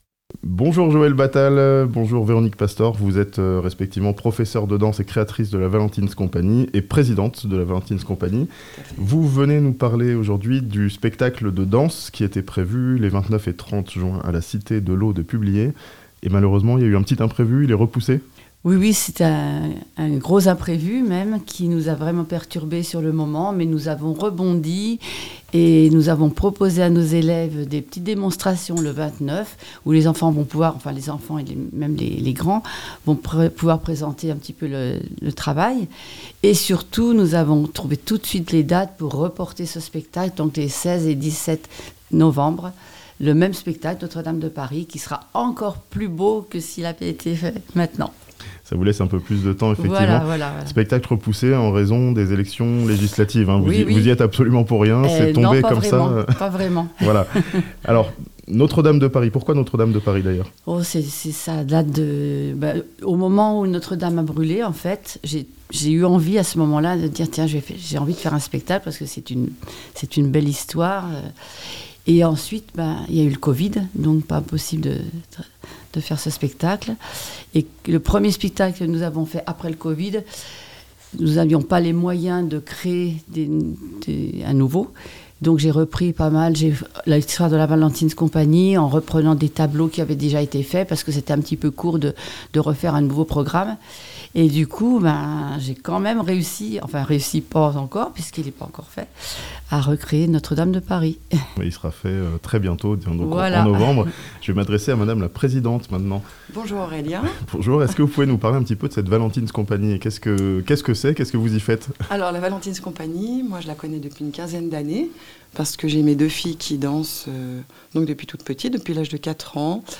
A Publier, le spectacle de danse de la Valentin's Company repoussé en raison des élections législatives (interview)